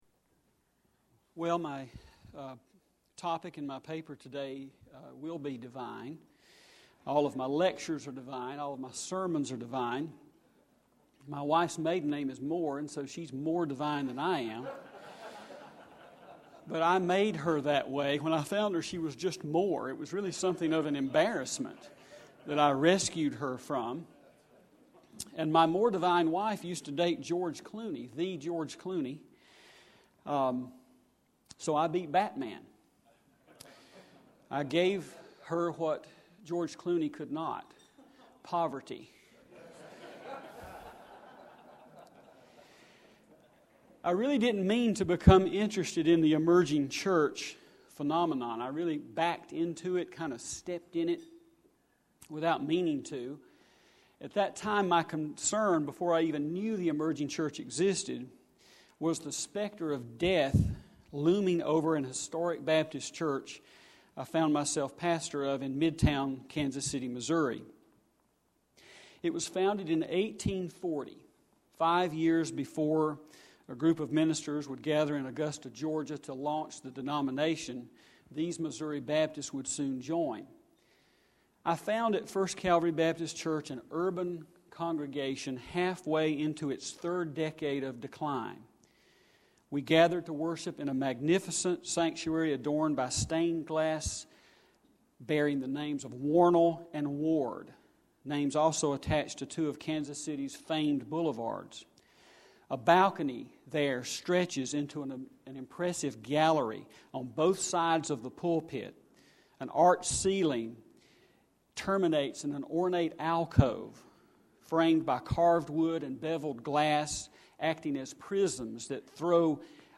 Future of Denominationalism Conference